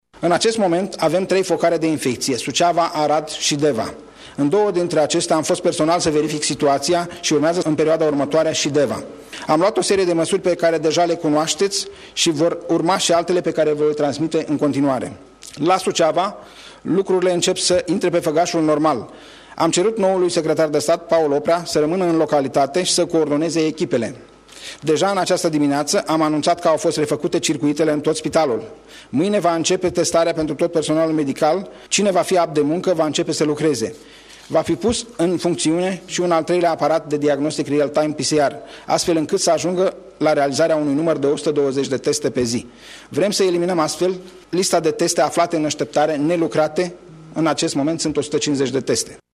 Ministrul Sănătății, Nelu Tătaru, a anunțat în urmă cu câteva minute zonele desemnate ca fiind cele mai mari focare de infecție cu coronavirus din Români. Este vorba despre municipiiile Suceava, Arad și Deva.